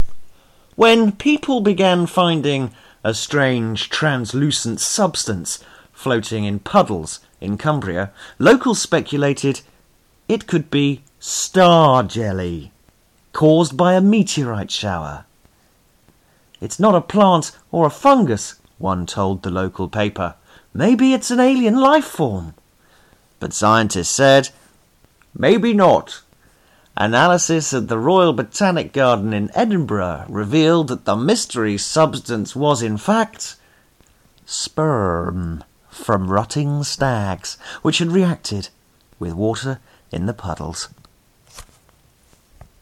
Rutting Stags